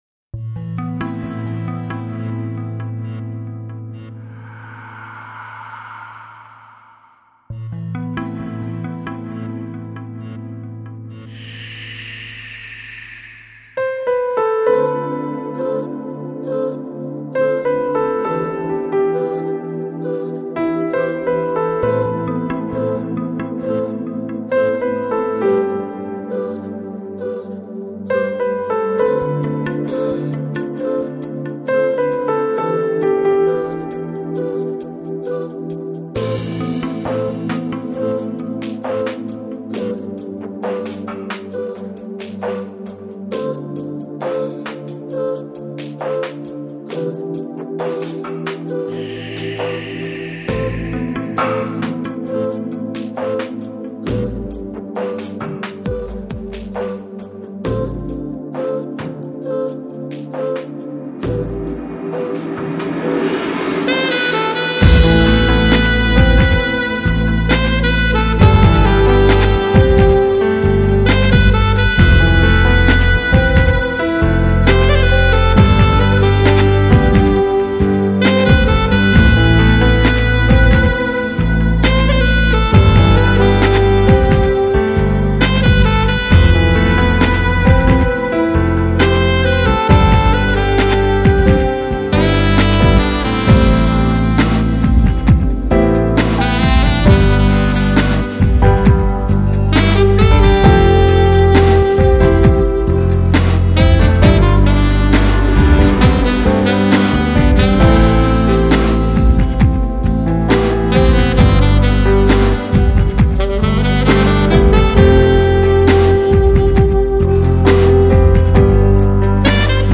Жанр: Downtempo, Lounge